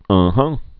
(ŭɴhŭɴ)